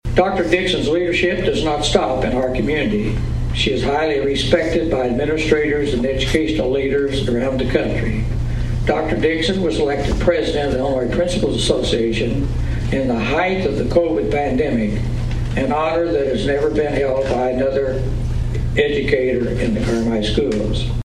This week’s Carmi Kiwanis Club meeting was highlighted by the announcement of the club’s Educator of the year and Support Staff of the year awards.